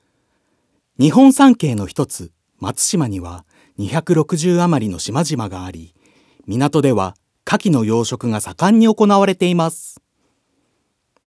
ナレーション2